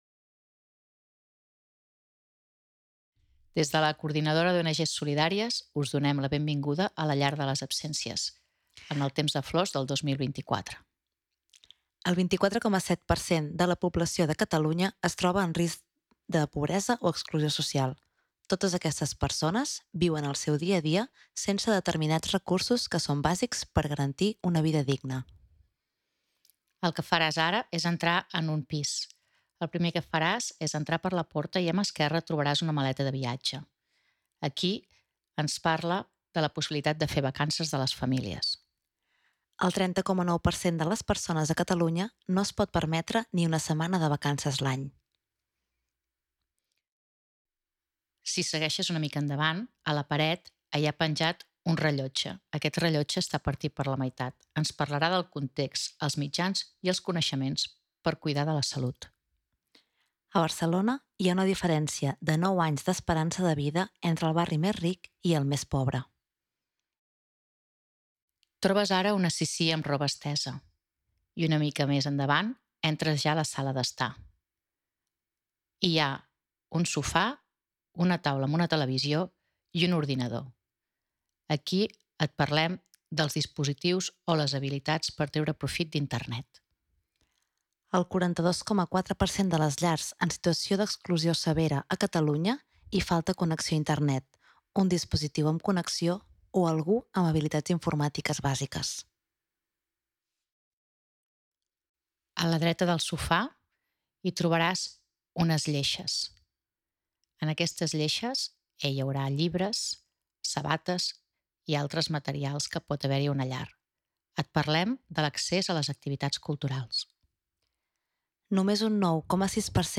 Audiodescripció de l'espai en CATALÀ